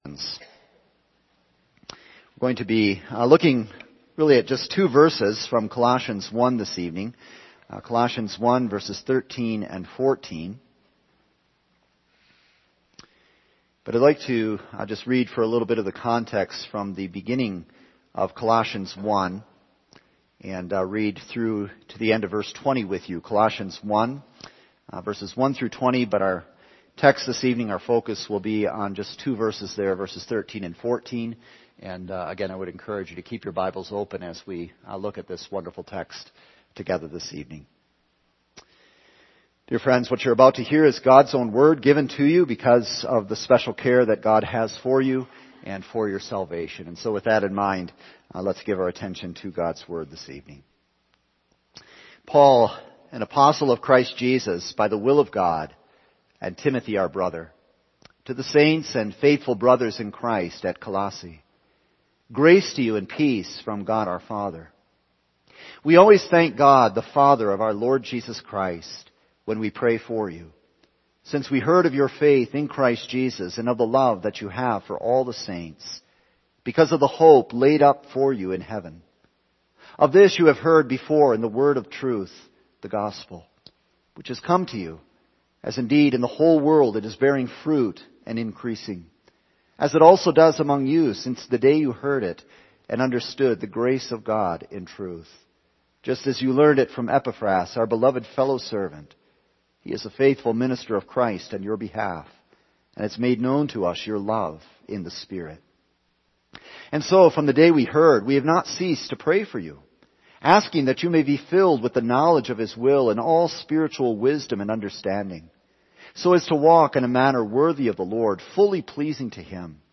All Sermons I Believe In …